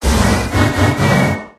Cri de Regidrago dans Pokémon HOME.